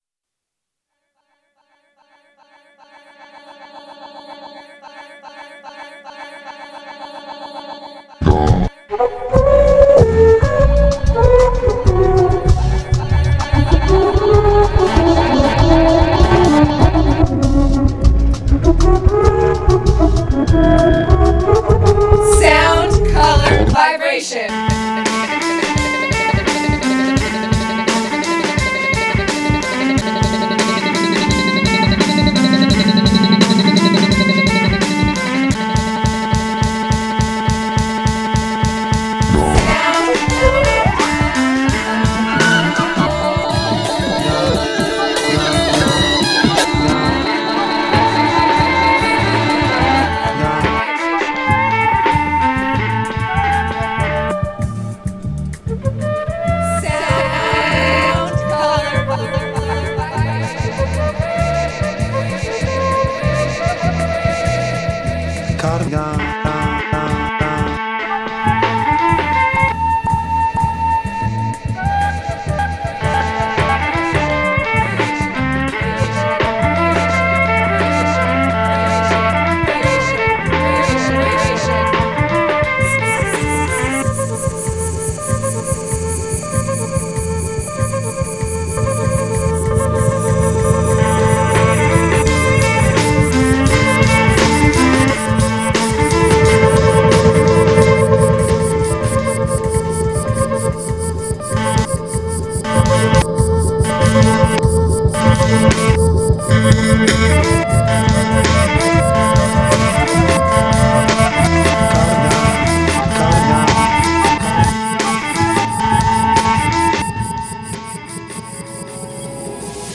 a manipulated Celtic bagpipe piece
Beats Electronic International Psych